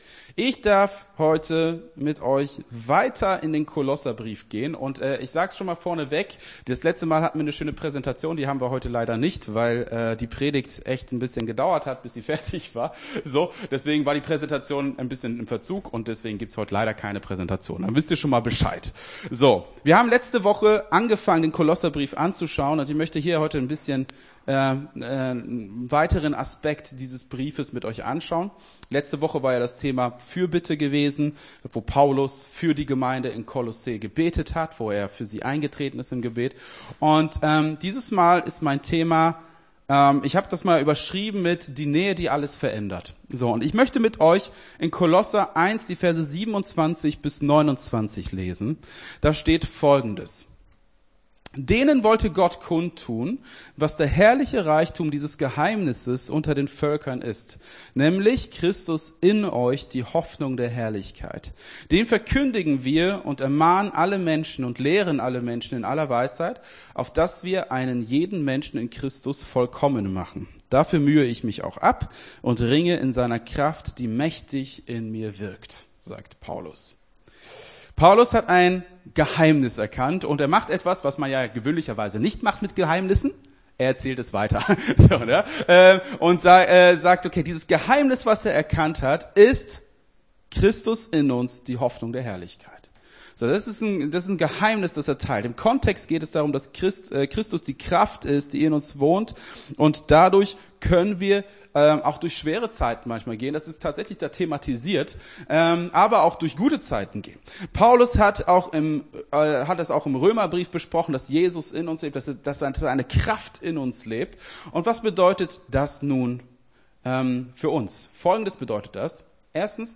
Gottesdienst 08.05.22 Kolosserbrief Teil 2 - FCG Hagen